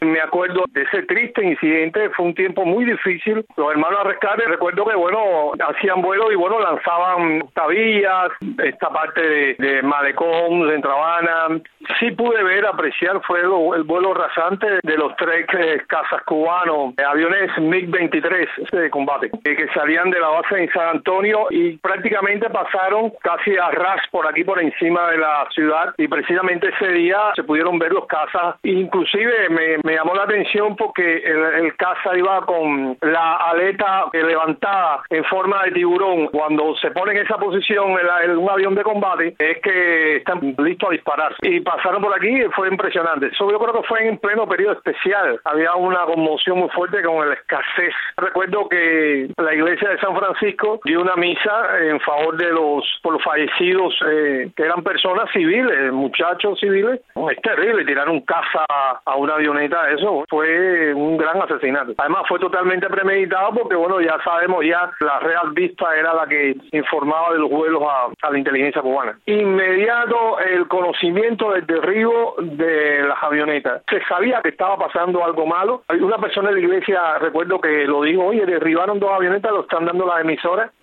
El evento se produjo en el Monumento a los Hermanos al Rescate, en el Aeropuerto Internacional de Opa-locka, sitio desde donde partieron las avionetas que fueron derribadas.